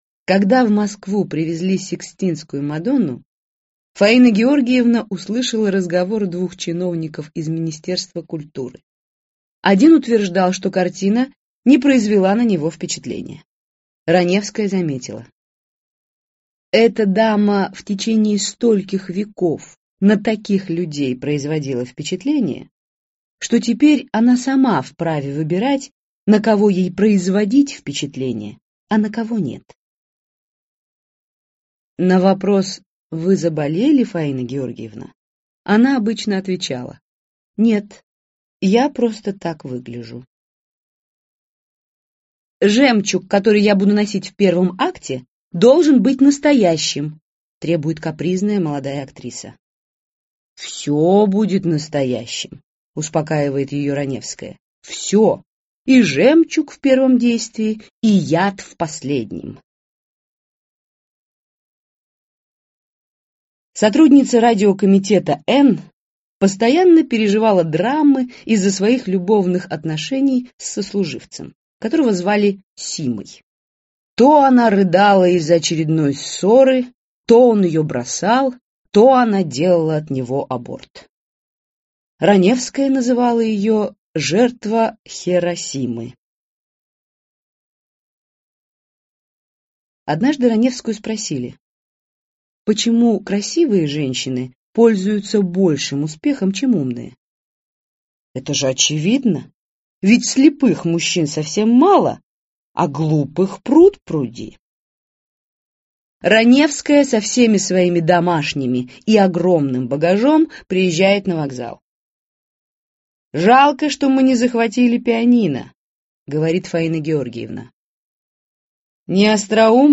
Аудиокнига Самые остроумные афоризмы и цитаты | Библиотека аудиокниг